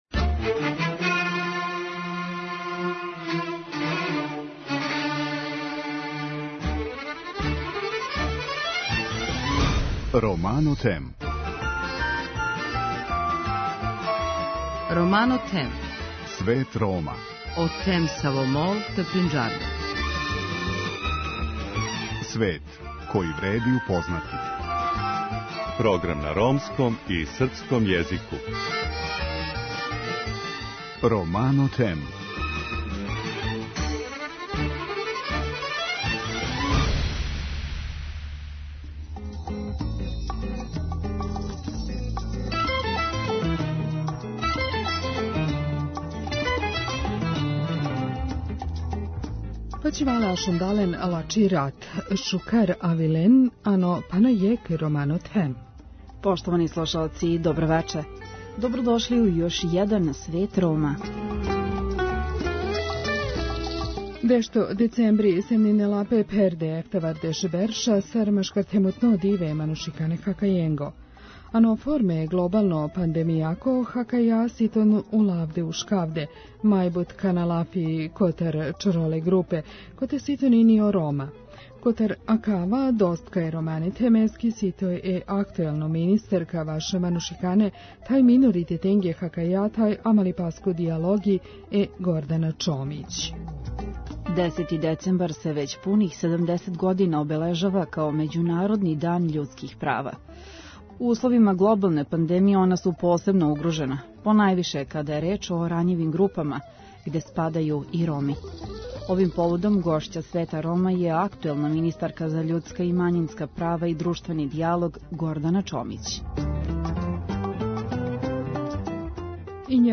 Гошћа емисије је министарка Гордана Чомић